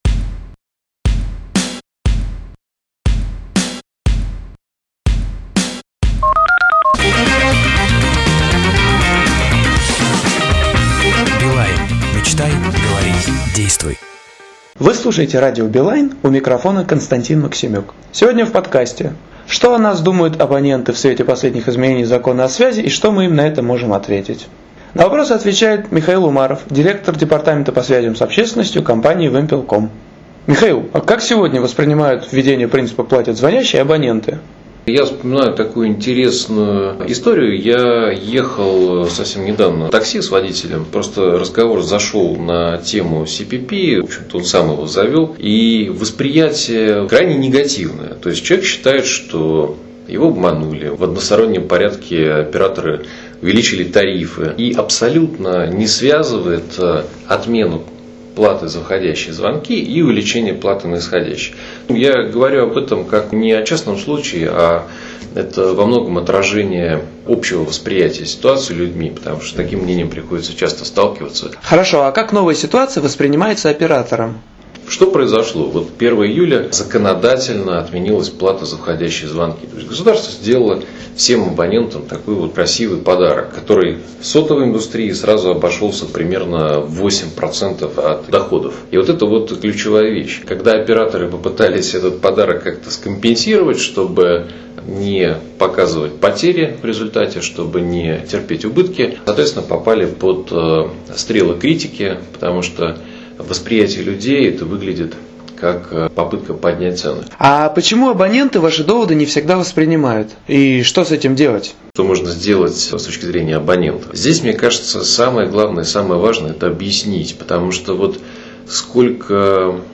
Что думают об операторе абоненты в свете последних изменений в законе "О связи"? Что оператор может им на это ответить? Эти и другие вопросы - в ролике с записью беседы